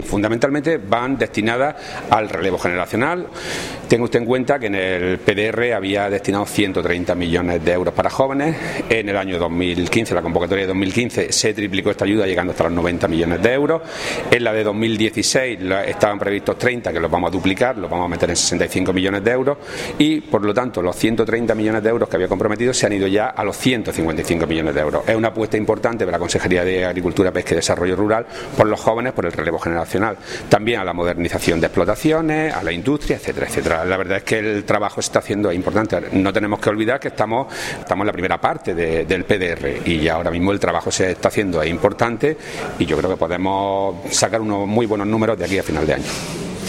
Declaraciones de Rodrigo Sánchez Haro sobre destino de los fondos del PDR comprometidos